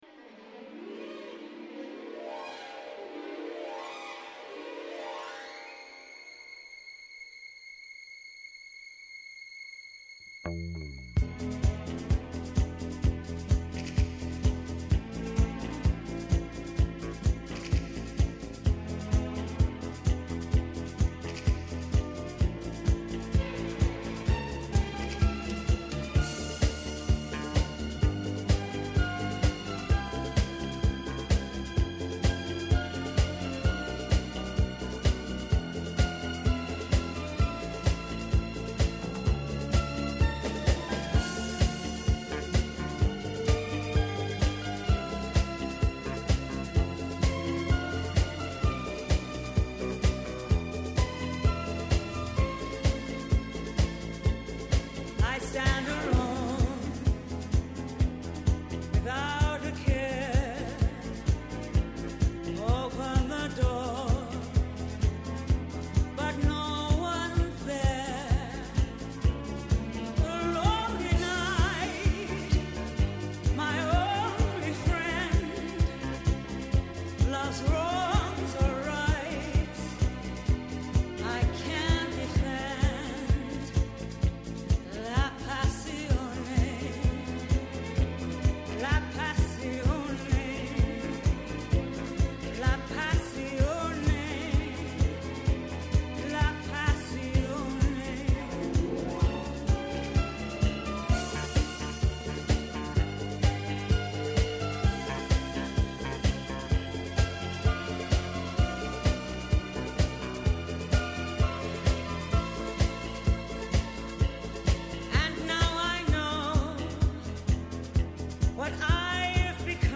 Mόνο στο Πρώτο Πρόγραμμα